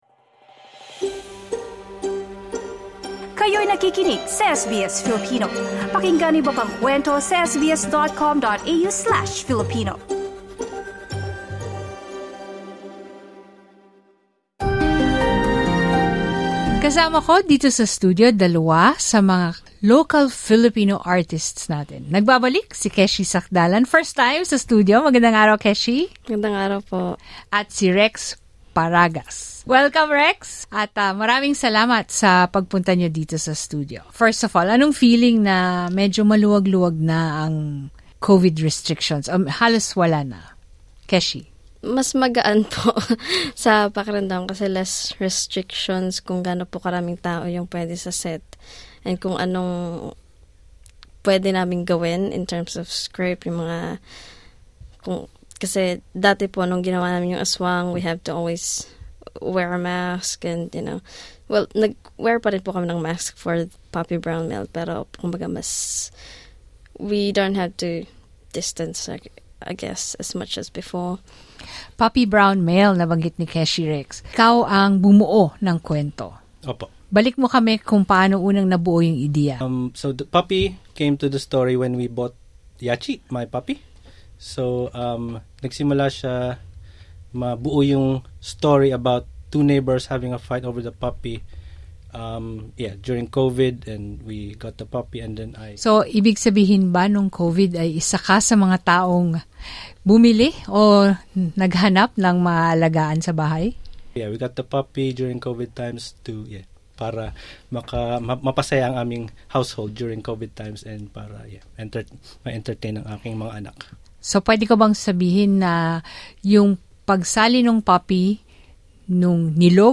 Listen to the interview 'Puppy